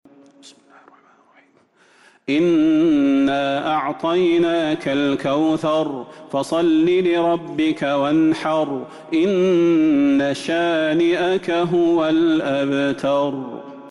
سورة الكوثر Surat Al-Kawthar من تراويح المسجد النبوي 1442هـ > مصحف تراويح الحرم النبوي عام ١٤٤٢ > المصحف - تلاوات الحرمين